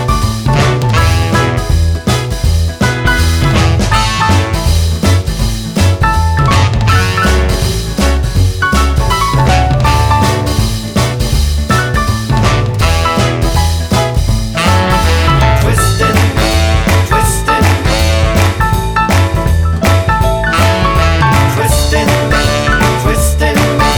Medley Soul / Motown